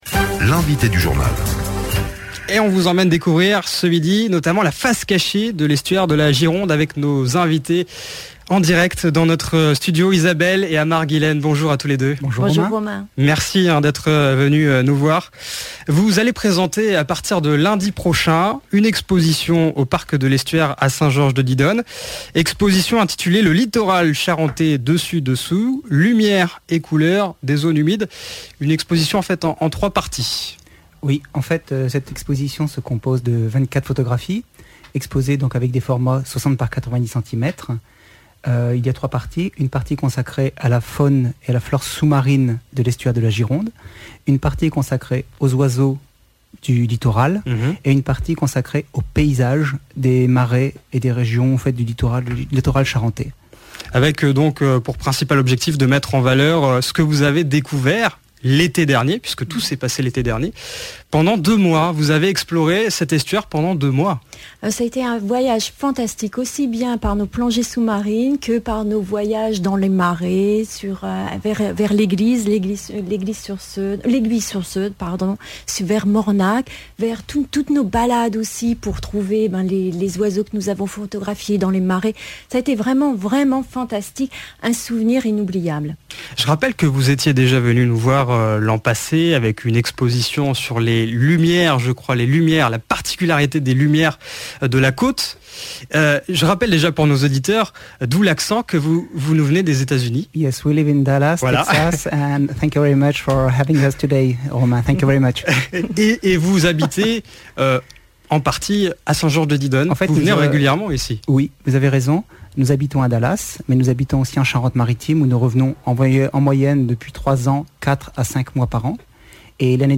Notre interview pour radio Demoiselle.